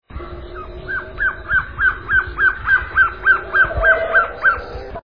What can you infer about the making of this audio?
Recordings were transferred from tape to a digital file (.wav) format. These songs represent our first try at converting the sounds to computer files, and some of the editing shows our lack of experience.